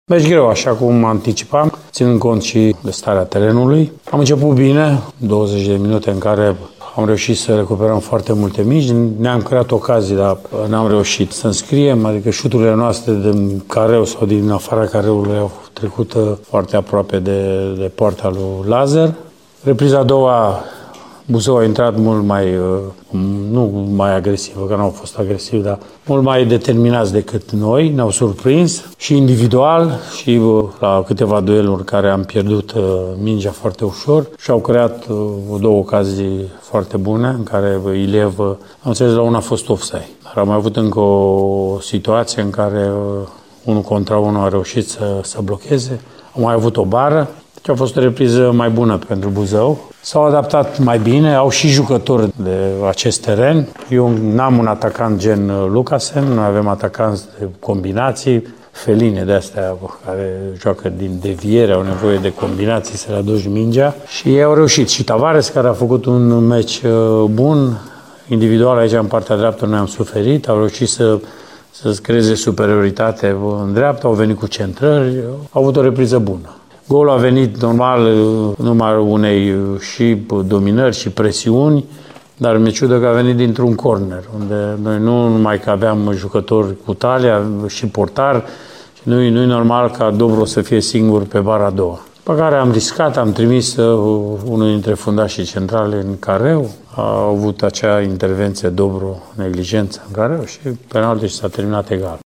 Antrenorul arădenilor, Mircea Rednic, cu caracterizarea meciului: